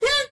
step3.ogg